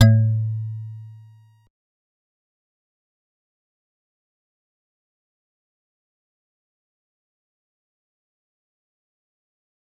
G_Musicbox-A2-pp.wav